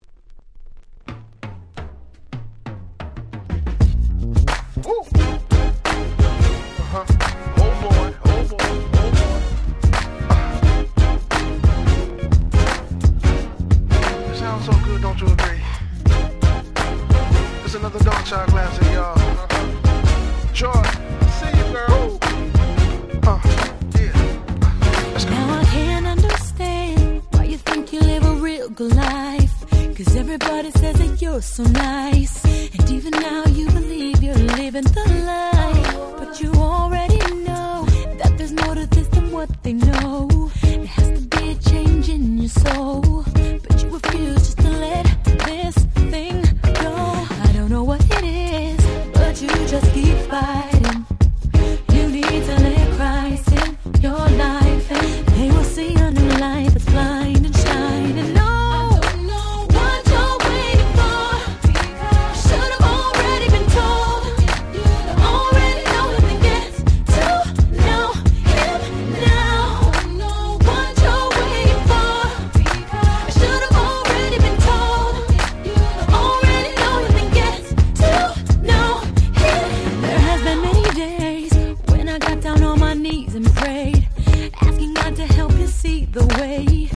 06' Nice R&B !!